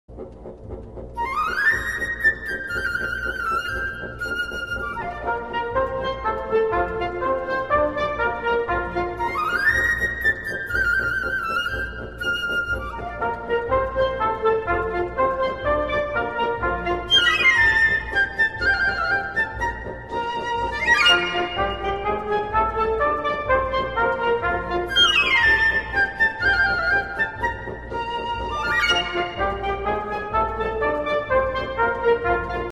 КЛАССИКА